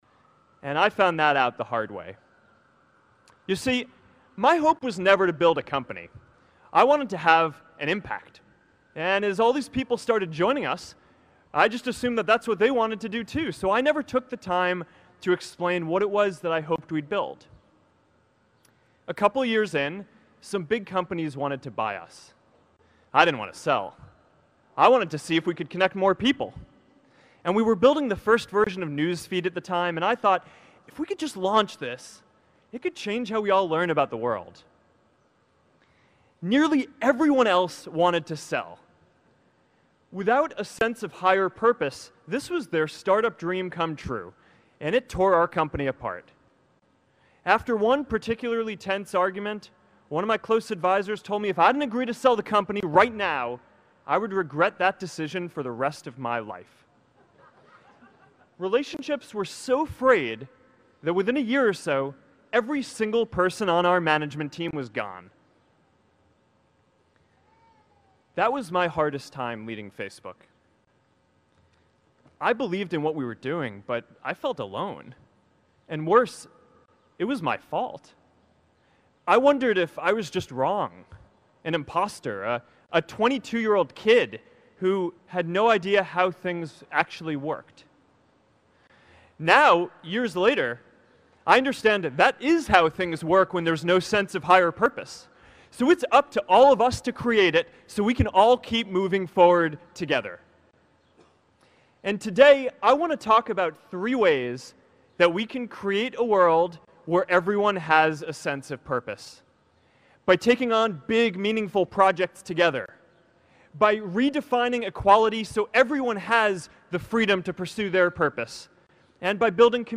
公众人物毕业演讲 第461期:扎克伯格2017哈佛毕业演讲(5) 听力文件下载—在线英语听力室